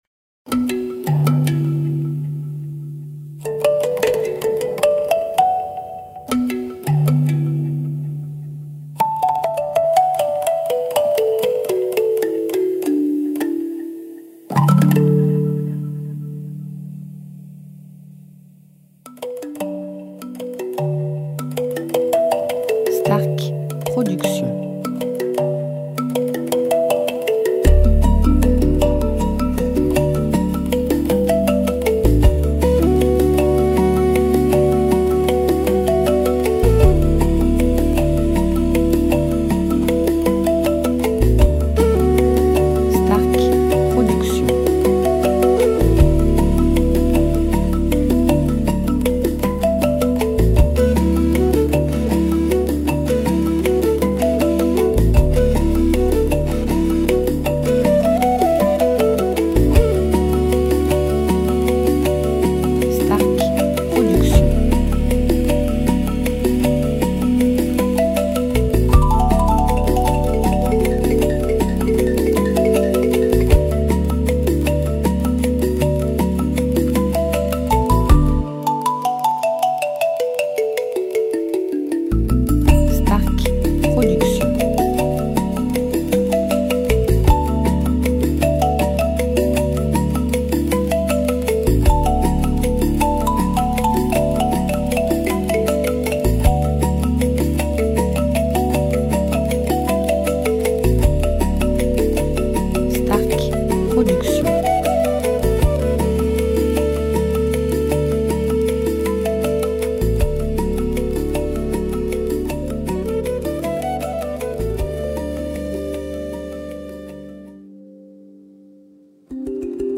style Relaxation Worldmusic durée 1 heure